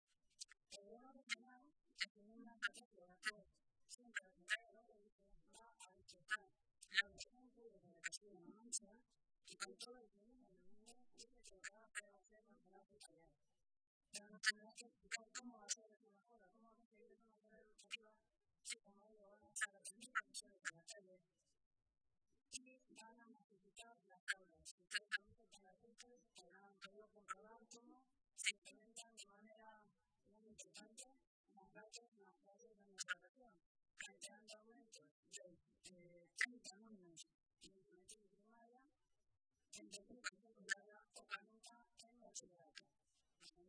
Mari Carmen Rodrigo, portavoz de Educación del Grupo Sacialista
Cortes de audio de la rueda de prensa